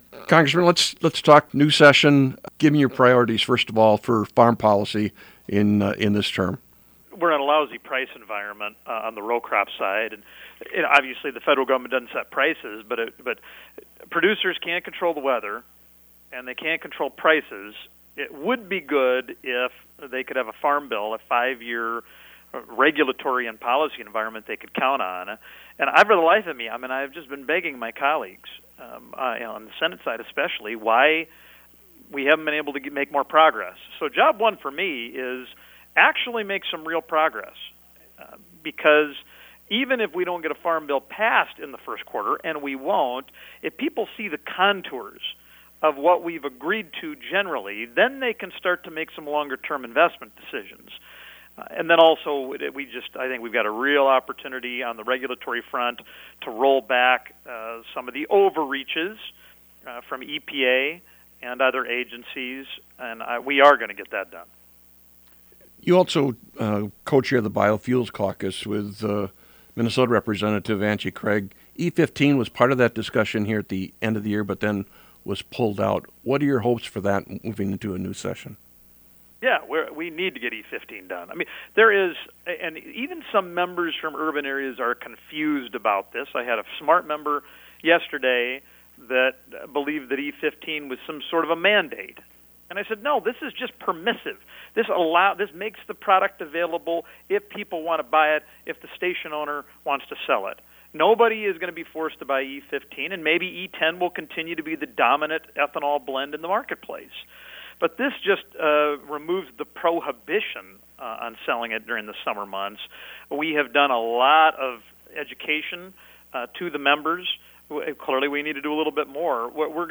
0107-Dusty-Johnson.mp3